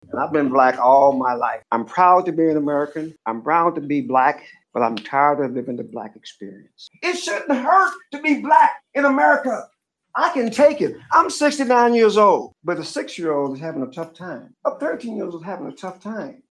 A packed crowd during Wednesday’s USD 383 meeting for public comment in regards to culturally responsive teaching and learning training.